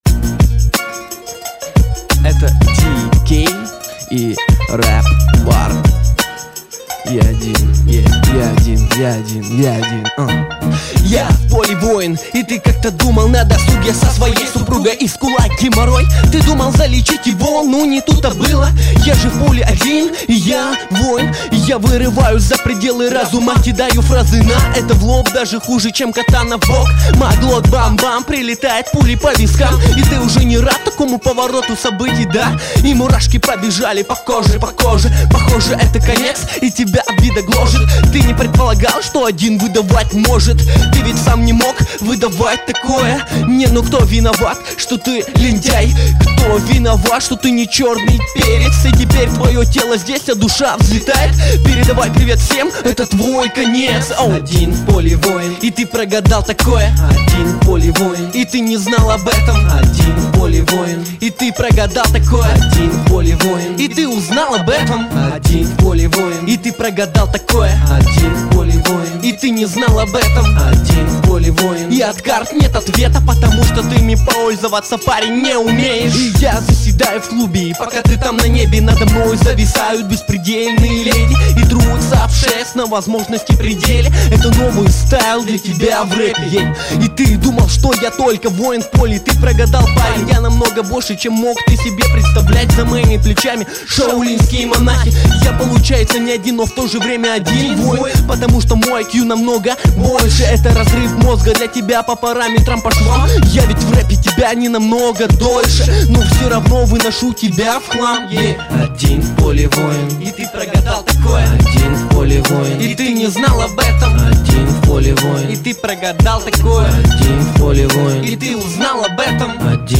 • Баттлы:, 2006-07 Хип-хоп
mp3,2114k] Джаз